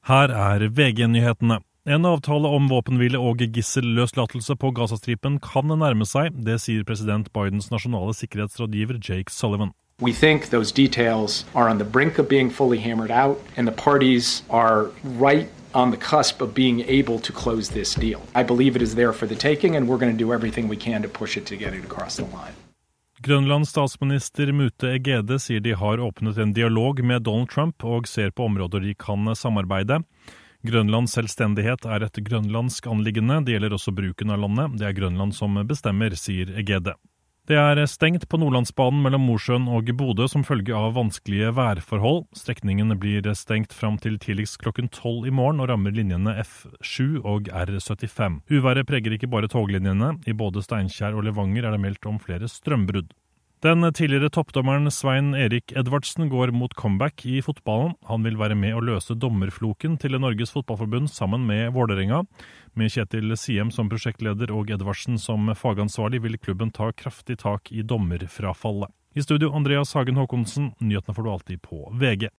The cool ocean breeze in your hair, the salt on your tongue.
A biweekly VGM podcast bringing you the jammiest video game music from all your favorite composers and consoles.